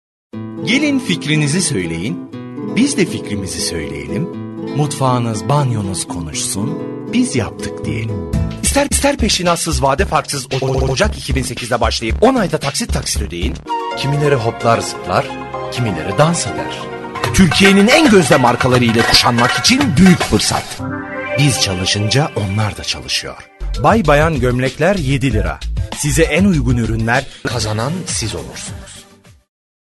Sprecher türkisch.
Sprechprobe: Werbung (Muttersprache):